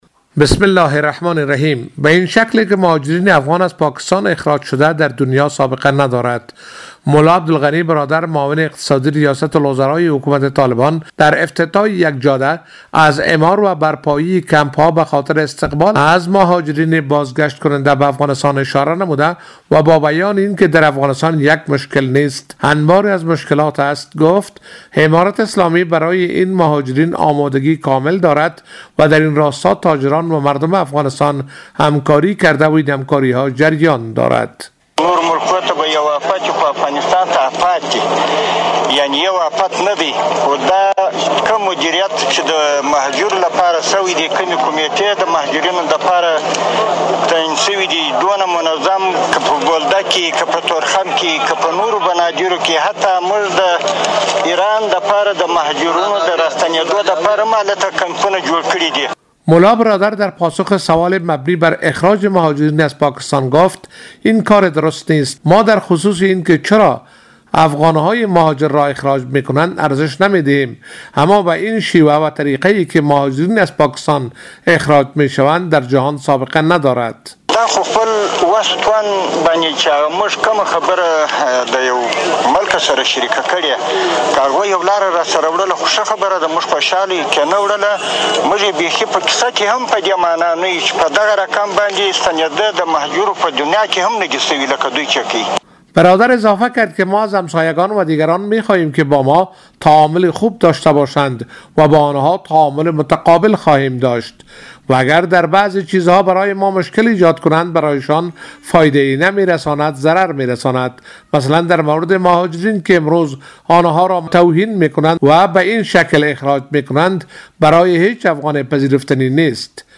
گزارش
ملا برادر در مراسم بهره برداری از یک جاده